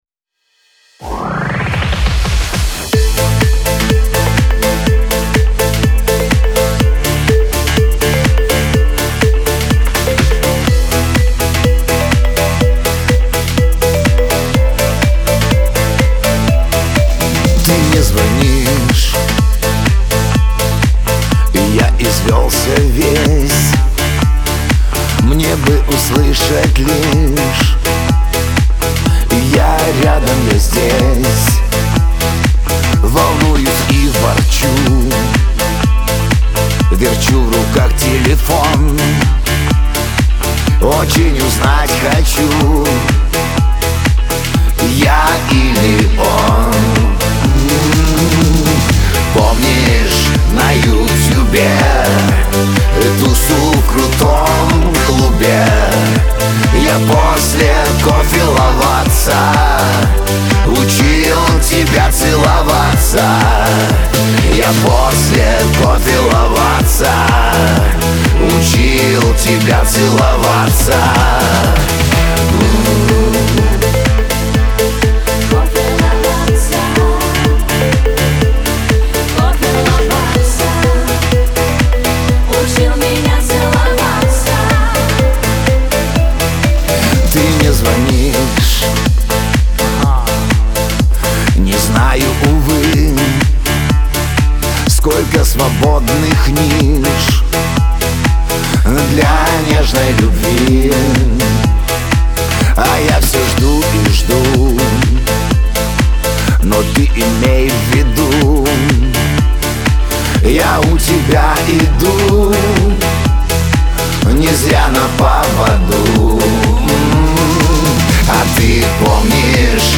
pop
эстрада